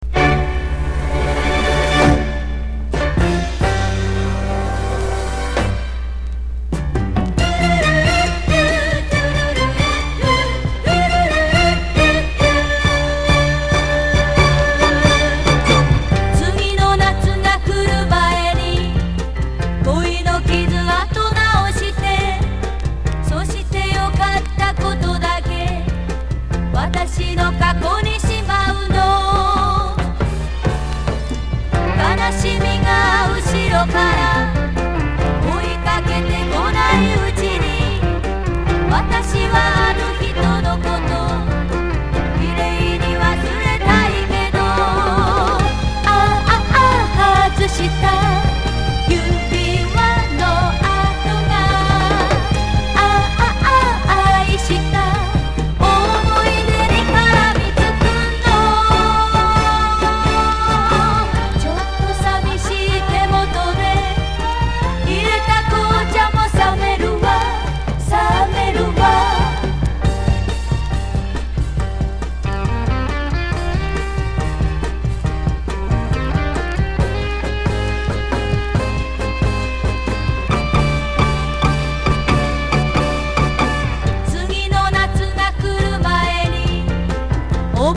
グルーヴィー歌謡!! 昭和歌謡ダンスパーティーチューン!!
コーラスも素晴らしい。バックバンドも素晴らしい演奏です。
POP